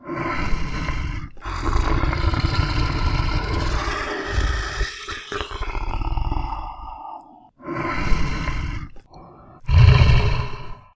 Creatures / Monsters / Angry Noises
angry-noises-1.ogg